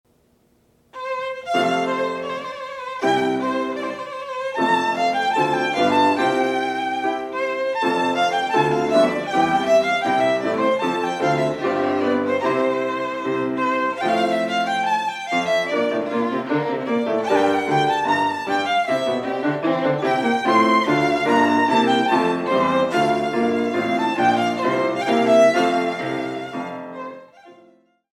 Musical Performances